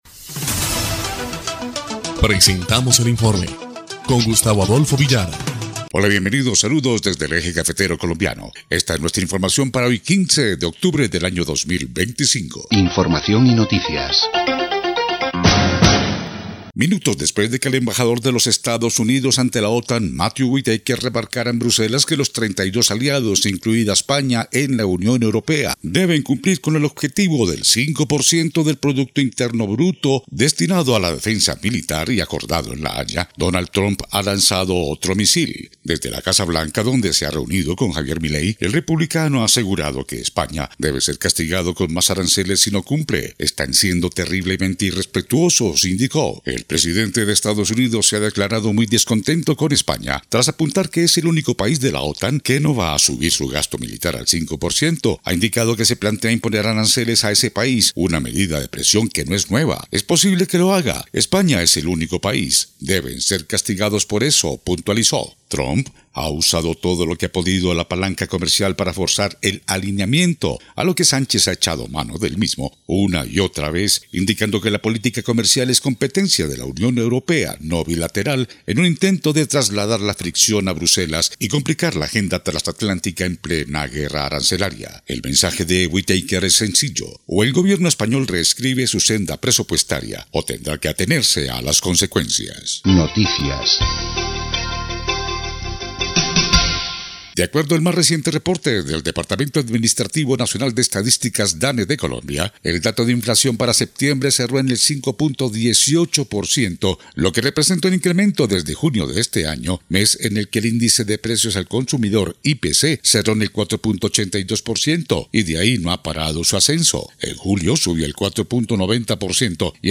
EL INFORME 2° Clip de Noticias del 15 de octubre de 2025